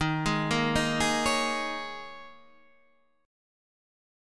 Eb7sus4 chord